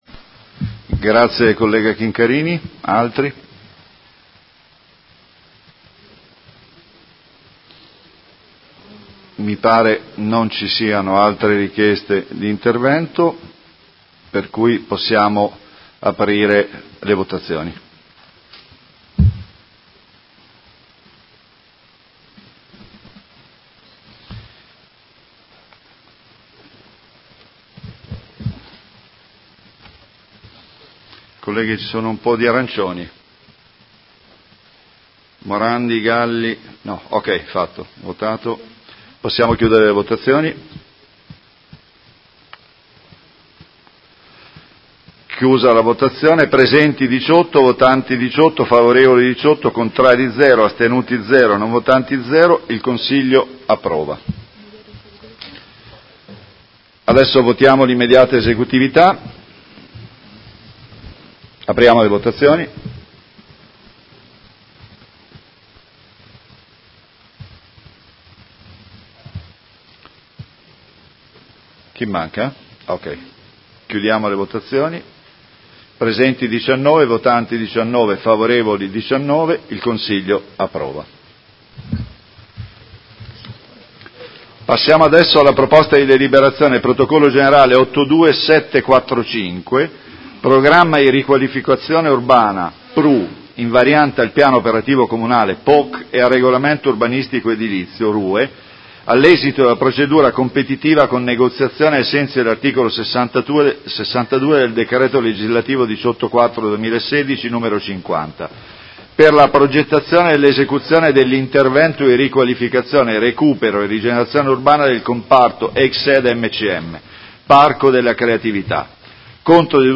Seduta del 04/04/2019 Mette ai voti la delibera. Convenzione con la Provincia di Modena per l’utilizzo del Difensore Civico Territoriale – Approvazione.